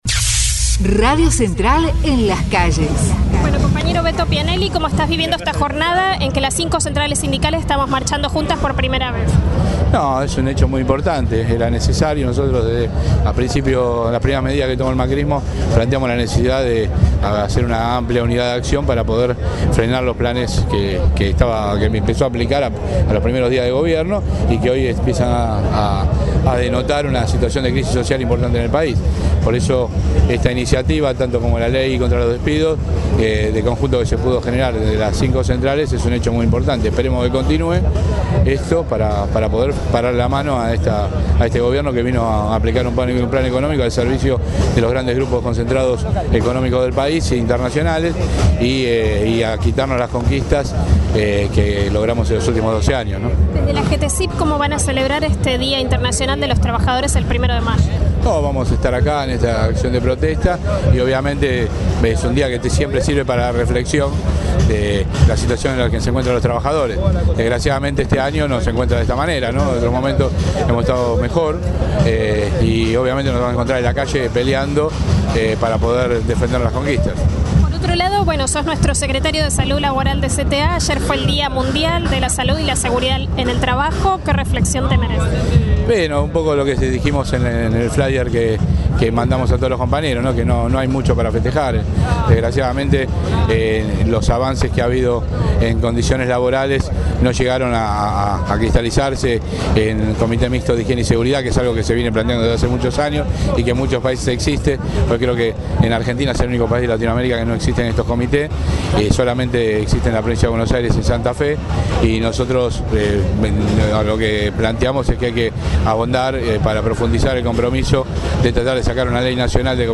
ACTO 1º DE MAYO